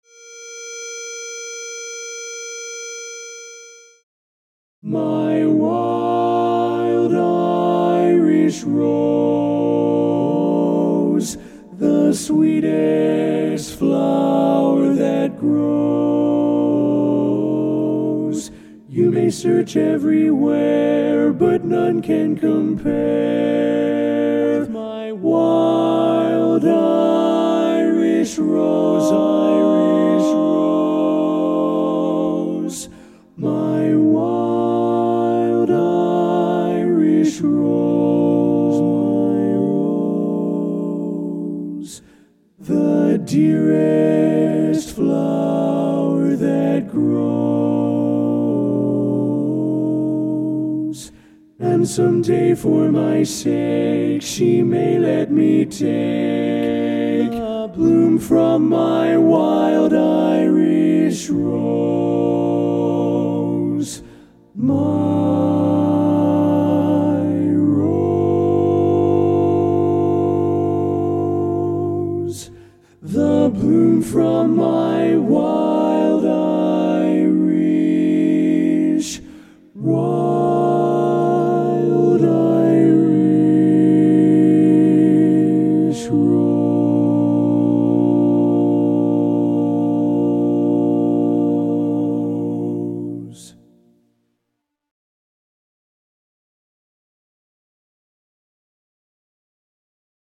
Kanawha Kordsmen (chorus)
Ballad
B♭ Major
Tenor